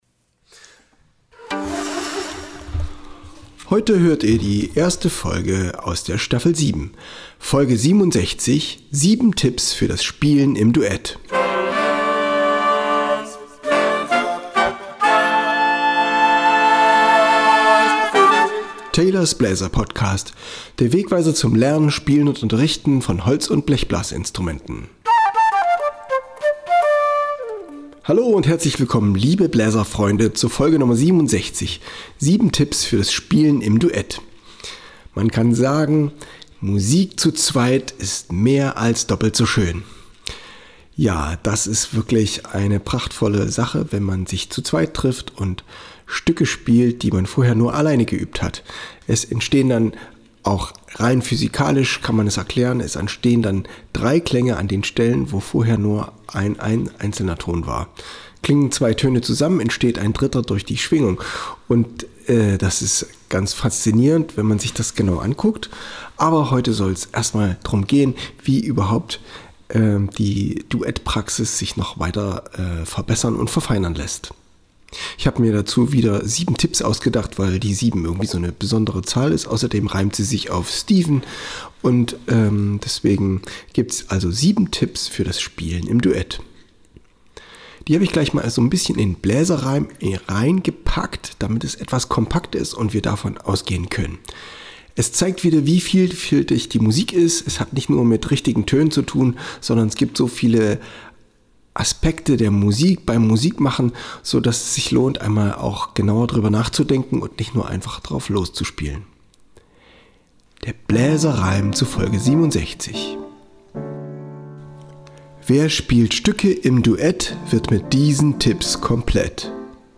Es heißt Chocolate On Friday und ich spiele es mit Posaune und Altsaxofon. Der Synthie hat sich angeboten, mit ein paar Harmonien zu polstern.